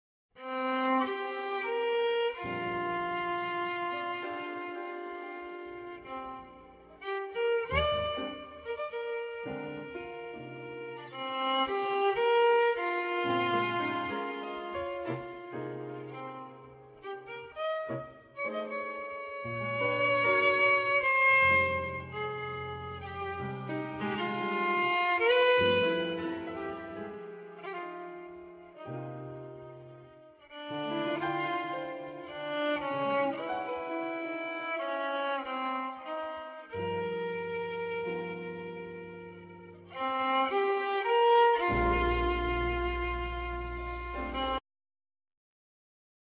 Violin
Piano
Bass
Drums